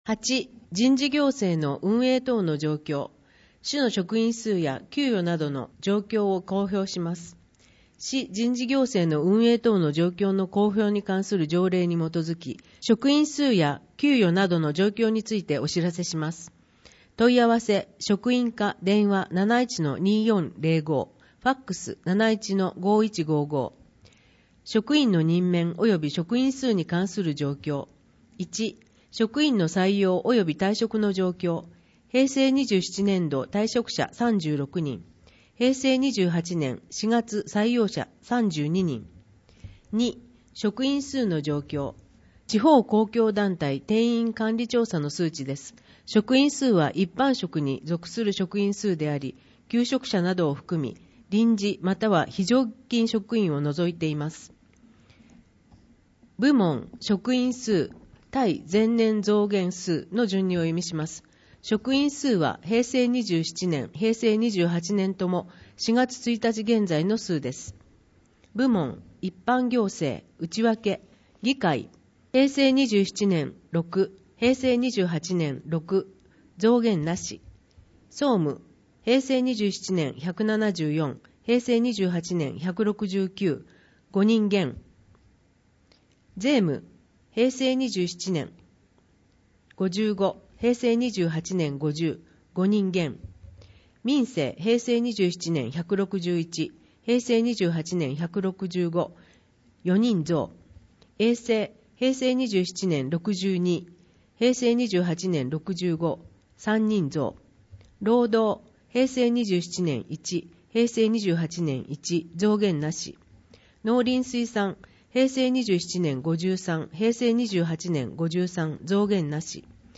広報あづみの朗読版237号（平成28年10月19日発行) - 安曇野市公式ホームページ
「広報あづみの」を音声でご利用いただけます。この録音図書は、安曇野市中央図書館が制作しています。